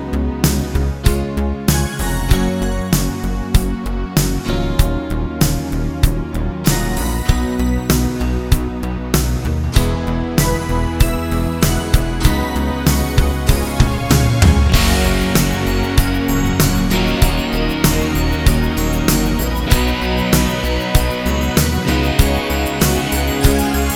no Backing Vocals Duets 4:10 Buy £1.50